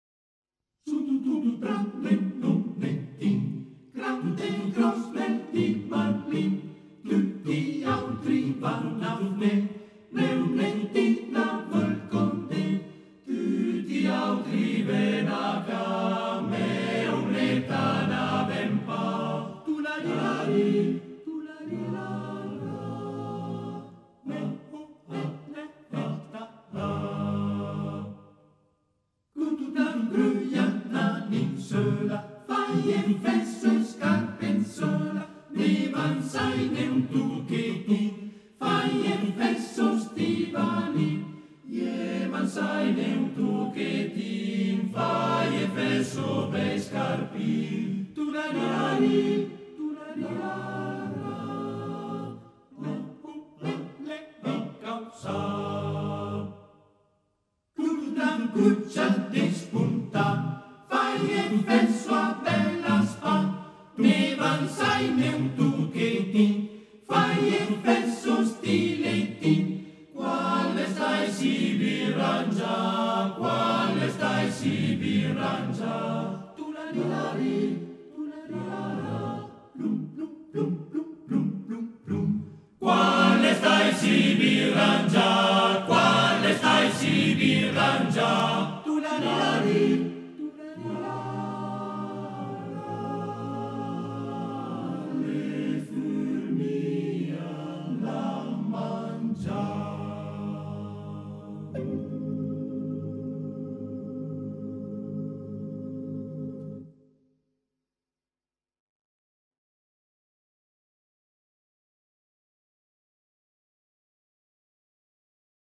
Arrangiatore: Benedetti Michelangeli, Arturo (armonizzatore)
Esecutore: Coro della SAT